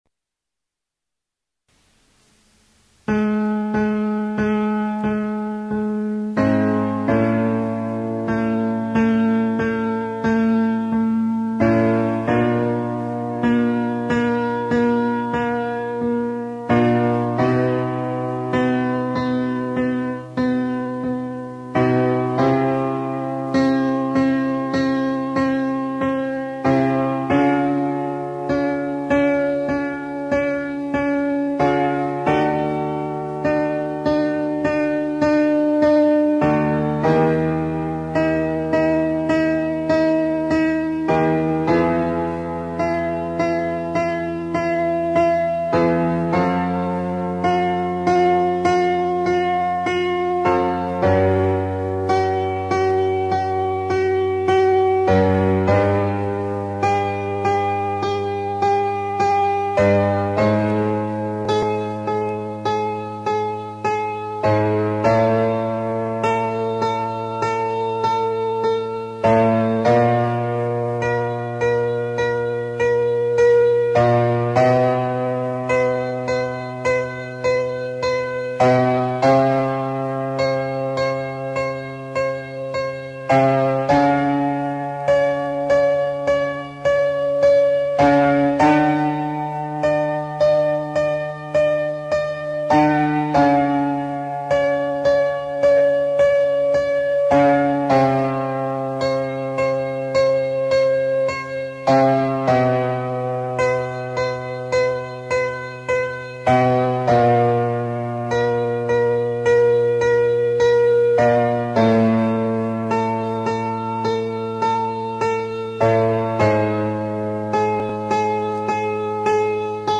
Warm Ups For Singers -
02 mee may mah maw moo.m4a
Mee May Mah Maw Moo - vowel placement and shaping